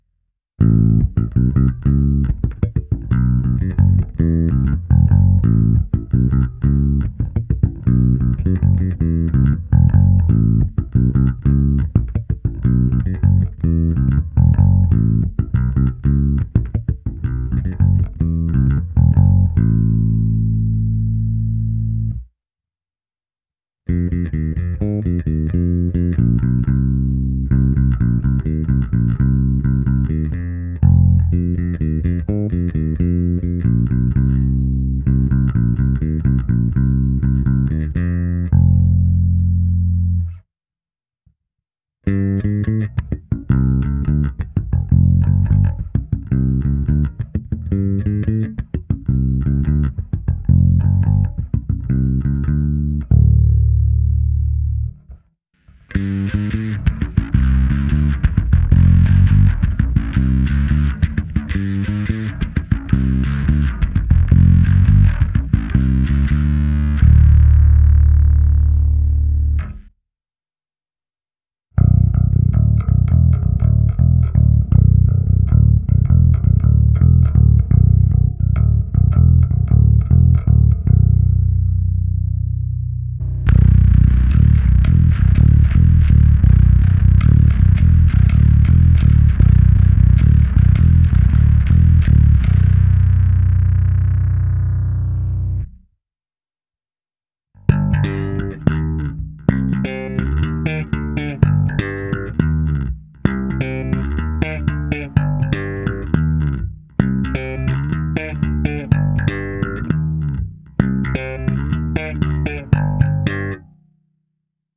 Nahrávka se simulací aparátu, kde bylo použito i zkreslení a hra slapem.